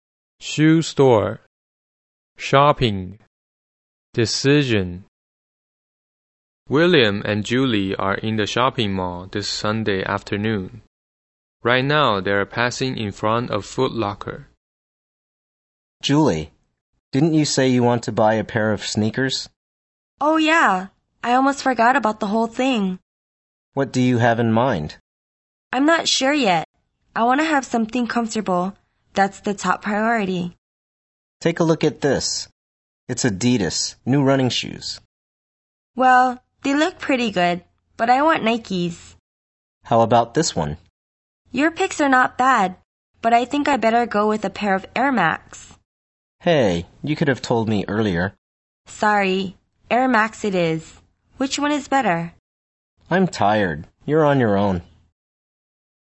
EPT美语 购物（对话） 听力文件下载—在线英语听力室